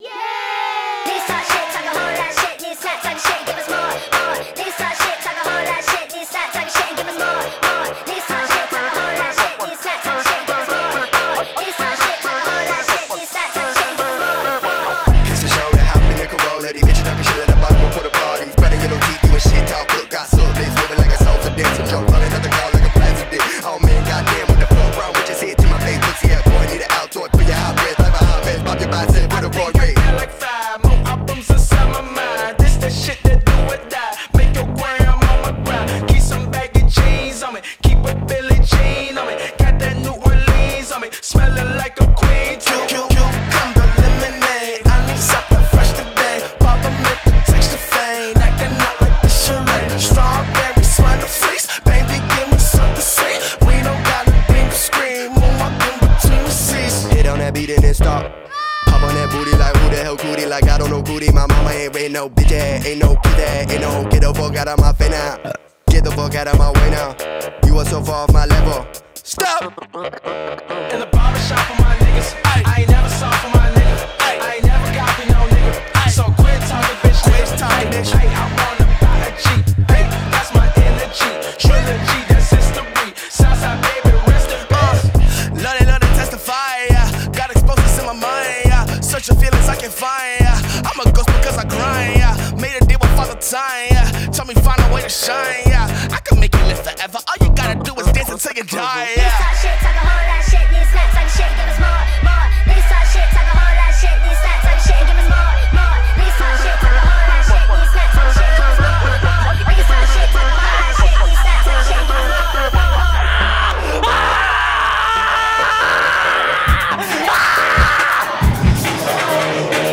BPM137
Audio QualityCut From Video